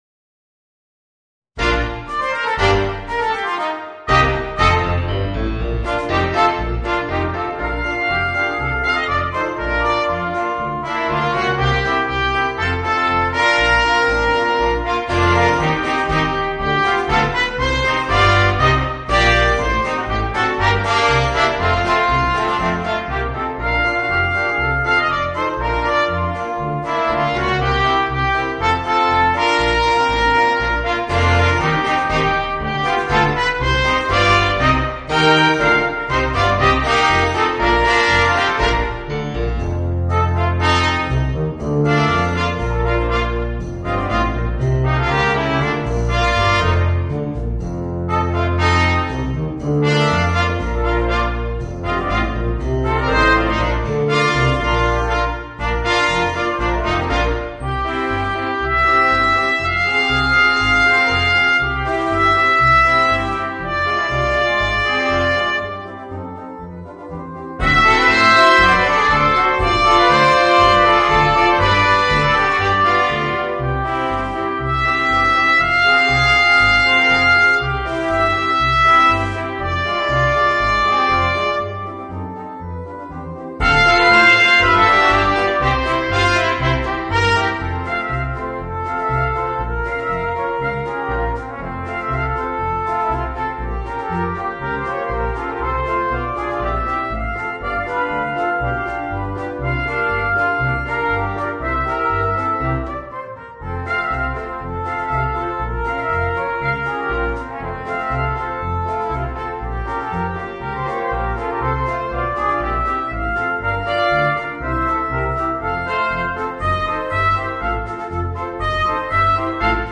Voicing: 2 Trumpets, Trombone and Tuba